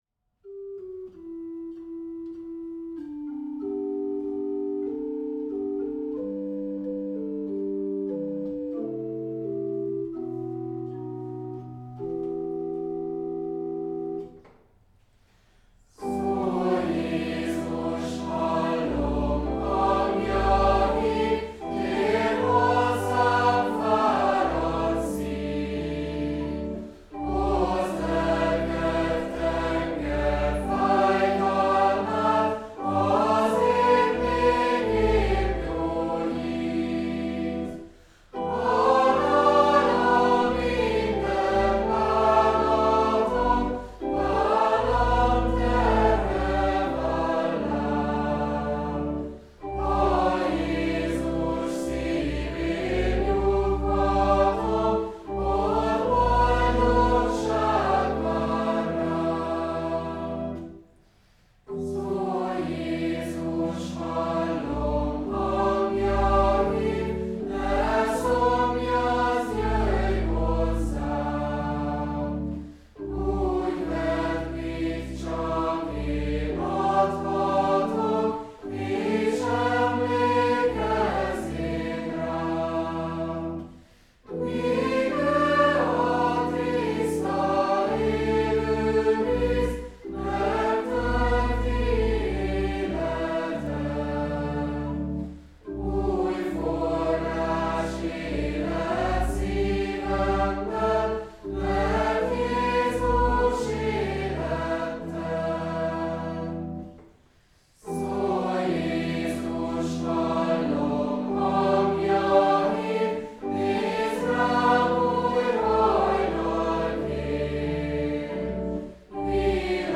Dallam: Tradicionális angol dallam 1893.
A hozzá kapcsolt népies dallam derűssé teszi a komoly tanítású verset, melynek mindegyik szakasza egy-egy jézusi mondásra épít.
A dallamot himnikus méltósággal és játékos könnyedséggel egyaránt megszólaltathatjuk.